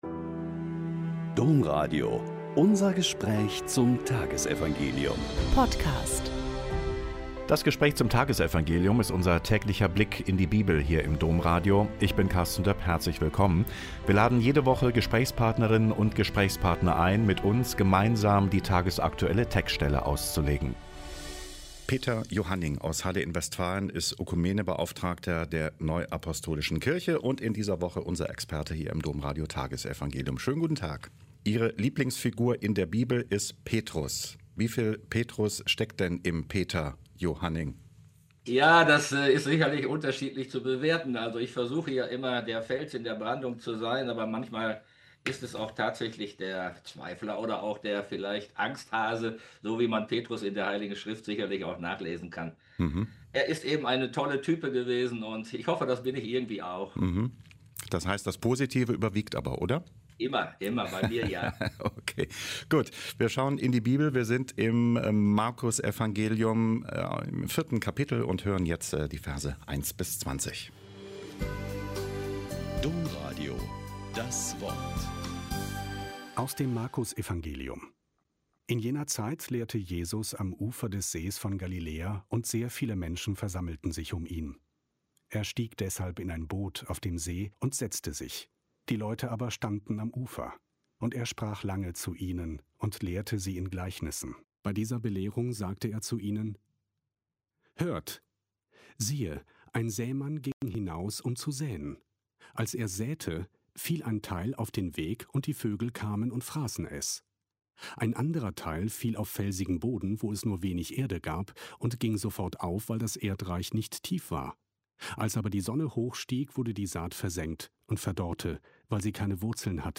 Mk 4,1-20 - Gespräch